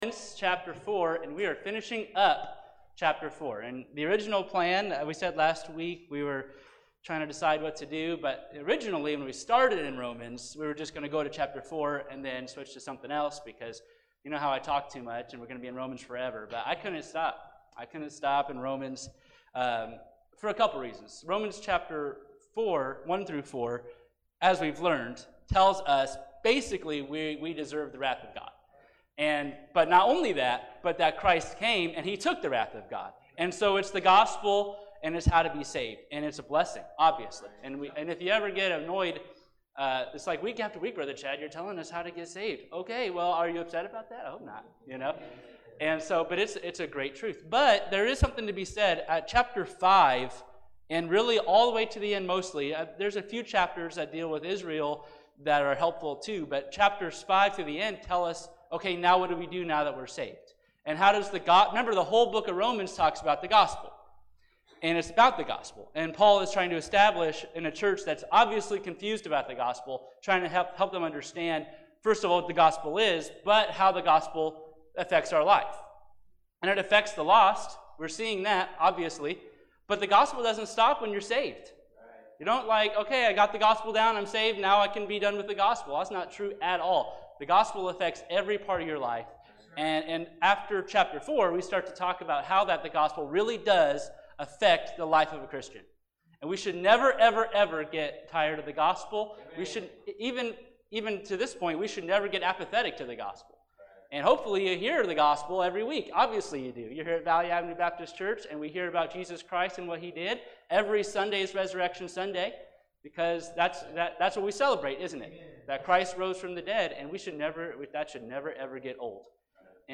Romans 4:17-25 – Lesson 16